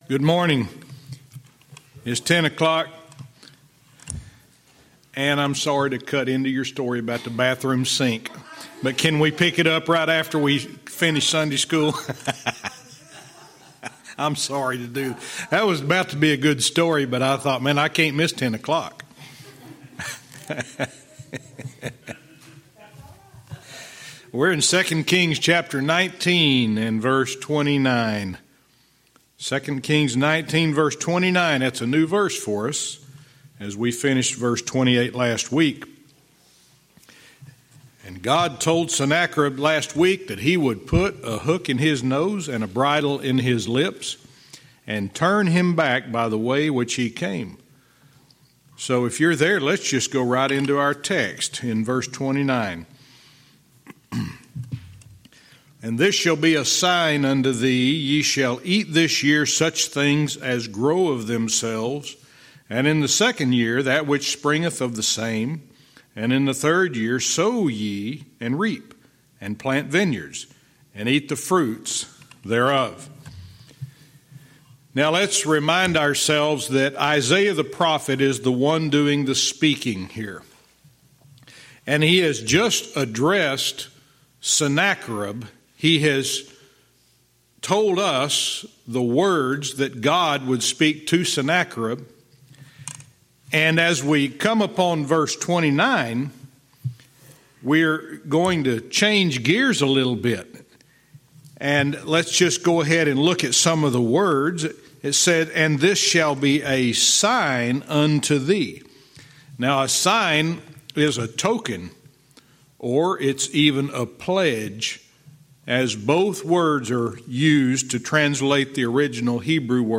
Verse by verse teaching - 2 Kings 19:29